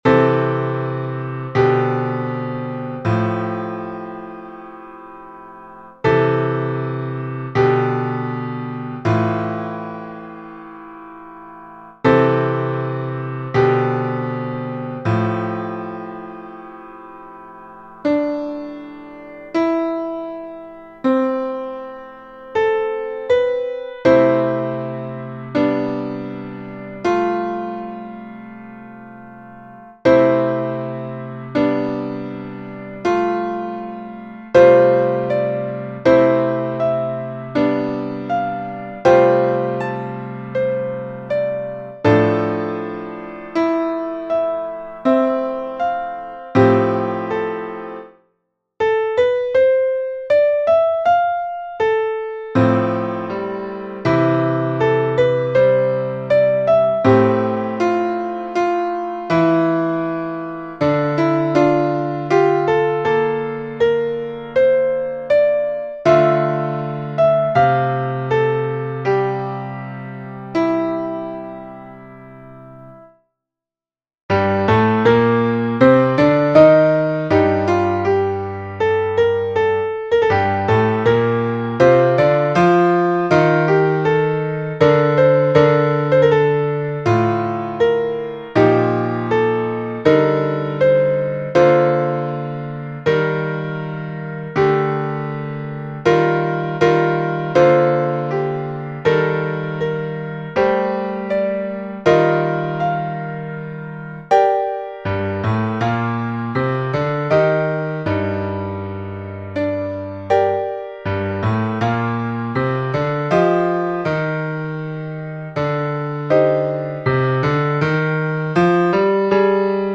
- Pièce pour chœur à 4 voix mixtes (SATB) + piano